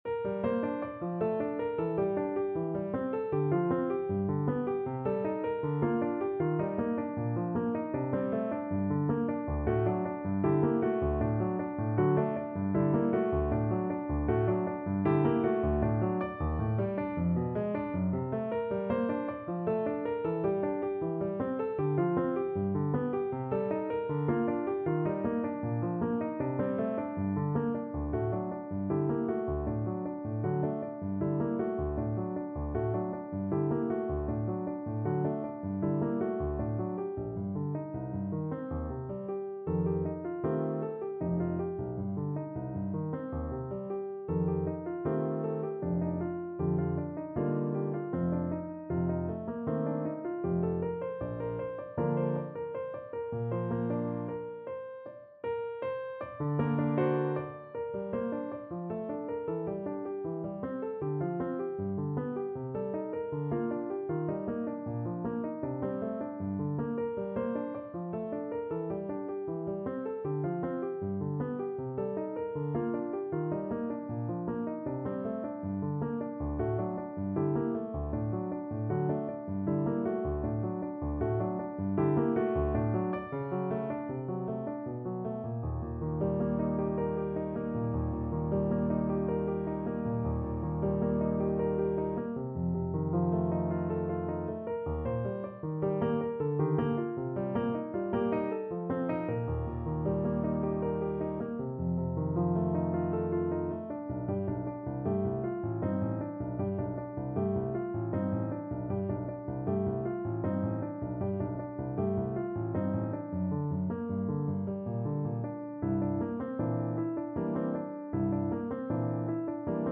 3/4 (View more 3/4 Music)
Classical (View more Classical Saxophone Music)